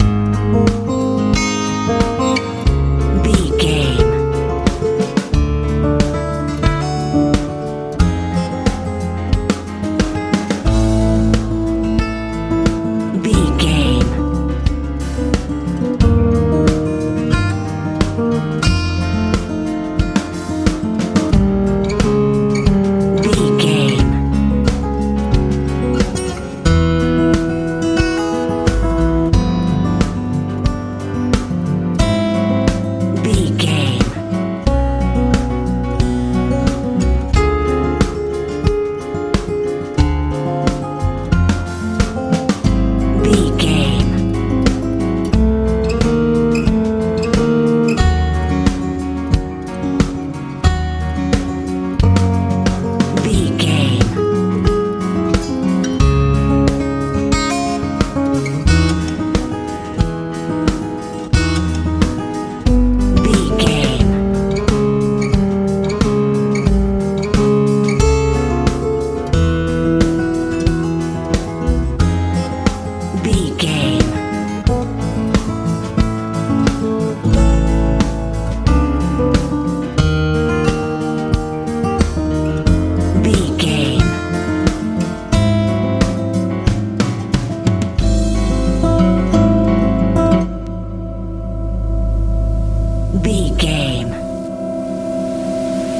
ballad feel
Ionian/Major
A♭
light
mellow
piano
acoustic guitar
bass guitar
drums
smooth
soft